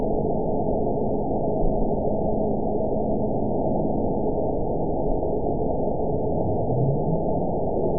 event 921745 date 12/18/24 time 08:17:50 GMT (10 months, 1 week ago) score 9.07 location TSS-AB02 detected by nrw target species NRW annotations +NRW Spectrogram: Frequency (kHz) vs. Time (s) audio not available .wav